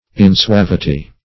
Meaning of insuavity. insuavity synonyms, pronunciation, spelling and more from Free Dictionary.
Search Result for " insuavity" : The Collaborative International Dictionary of English v.0.48: Insuavity \In*suav"i*ty\, n. [L. insuavitas: cf. F. insuavit['e].